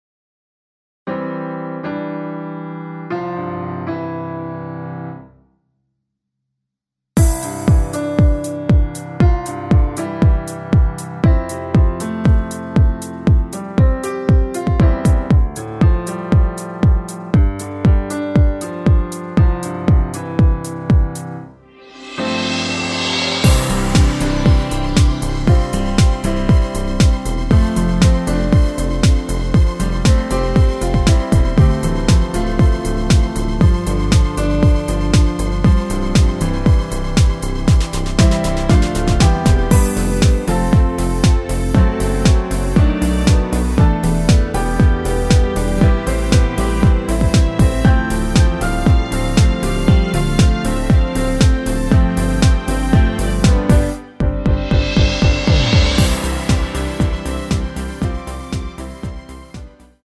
전주가 없는 곡이라 2마디 전주 만들어 놓았습니다.(미리듣기 참조)
원키에서(-2)내린 MR 입니다
앞부분30초, 뒷부분30초씩 편집해서 올려 드리고 있습니다.